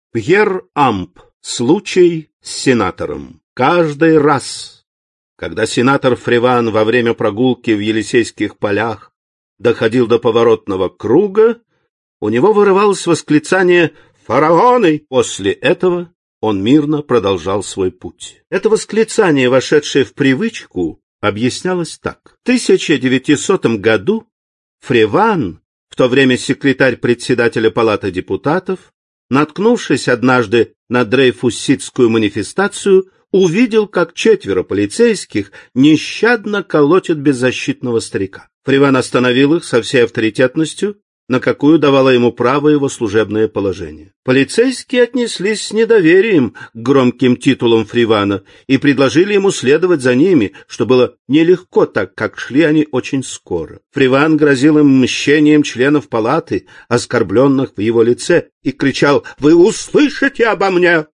Aудиокнига Классика зарубежного рассказа 6 Автор Сборник Читает аудиокнигу Сергей Чонишвили.